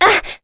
Chrisha-Vox_Damage_01.wav